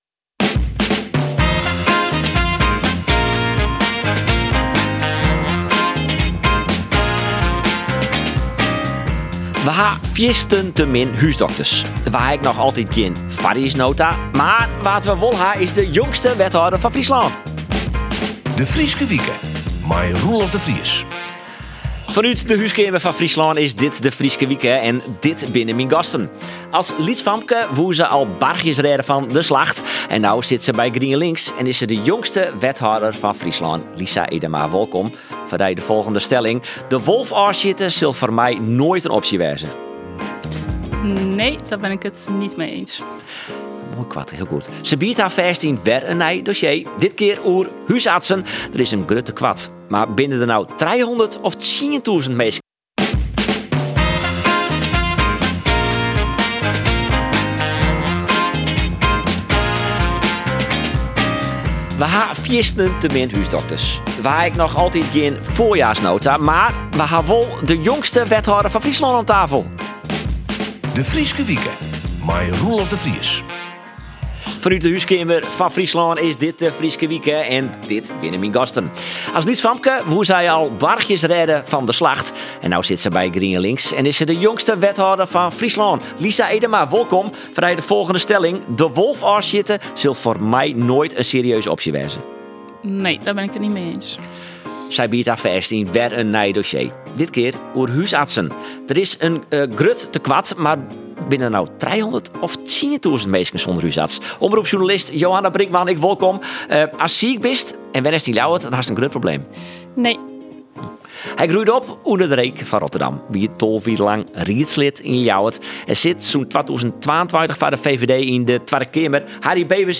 Hy ûntfangt nijsgjirrige gasten dy't in wichtige rol spylje yn it nijs en op it politike fjild.
Hij ontvangt nieuwsgierige gasten die in belangrijke rol spelen in het nieuws en op het politieke speelveld.